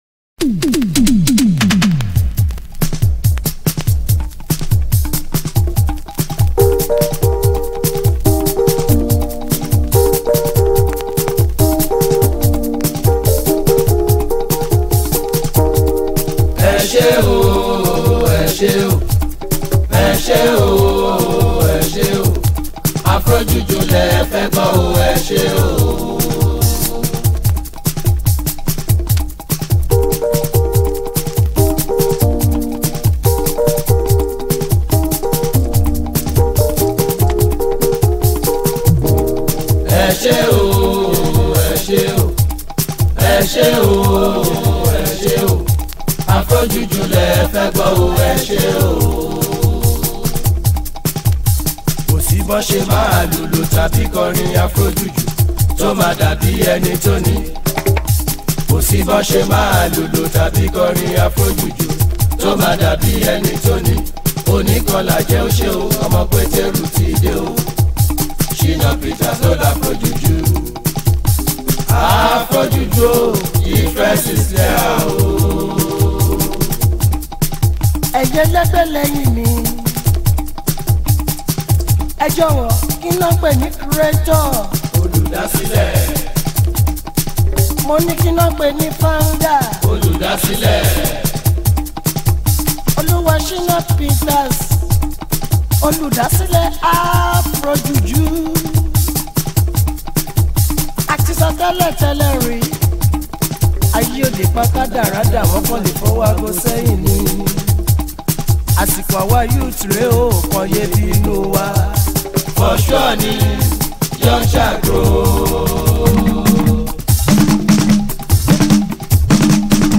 is a Nigerian Jùjú musician.